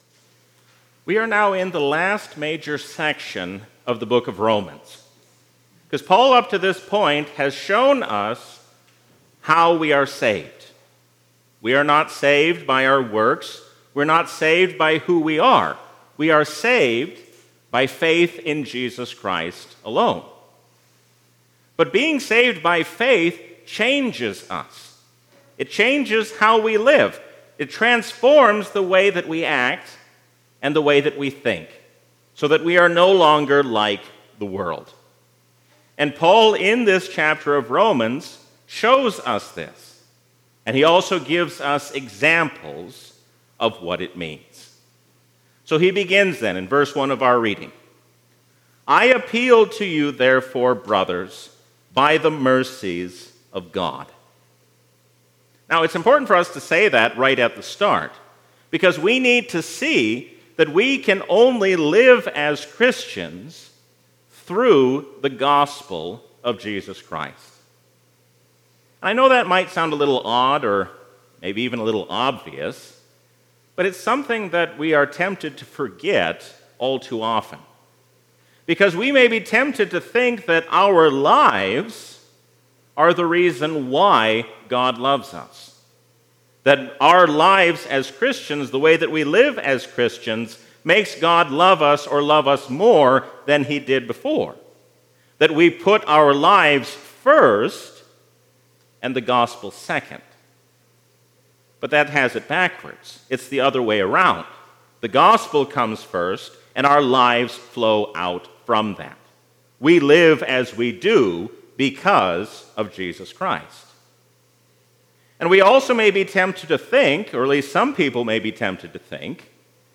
A sermon from the season "Trinity 2022." Are we a copy of the world or a copy of God?